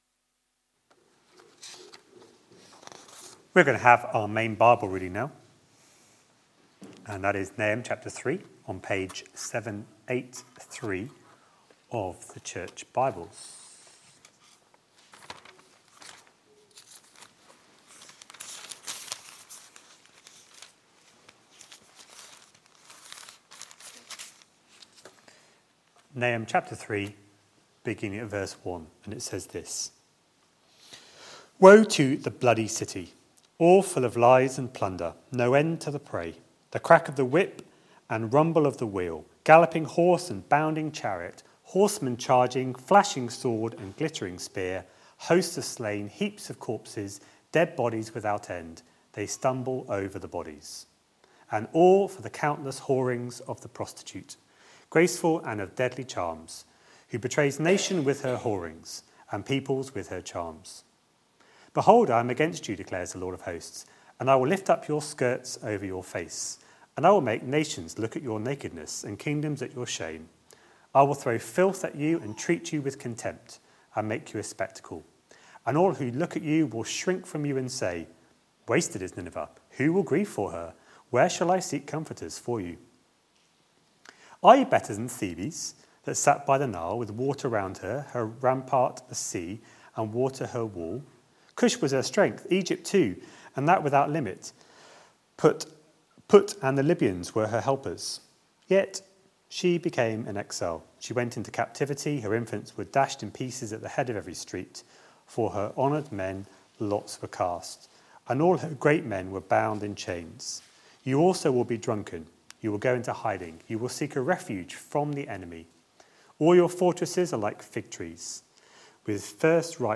A sermon preached on 26th August, 2018, as part of our Nahum series.